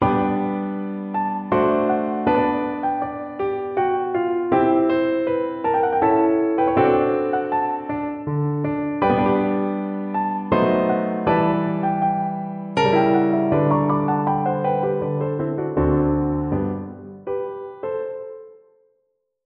pdfmp3Andrée, Elfrida, Fem smärre tonbilder, Op.7, IV. Andante, mm.13-16